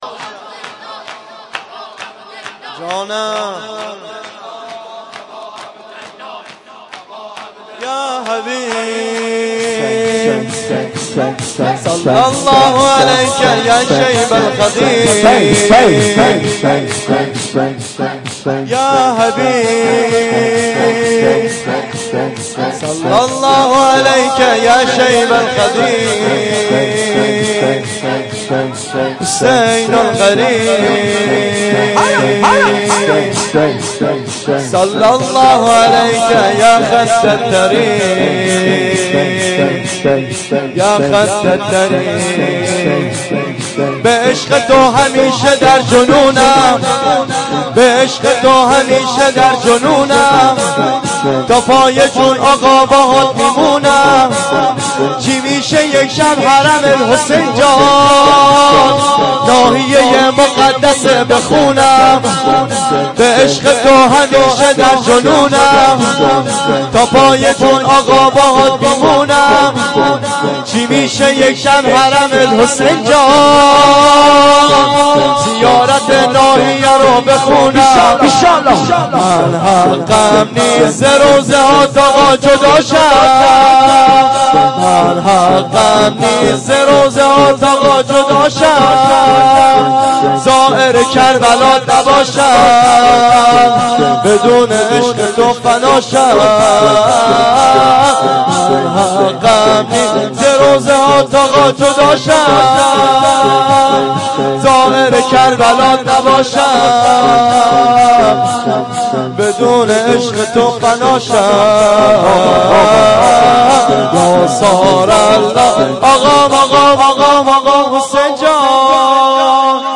شور
جلسه هفتگی 26-9-93 .mp3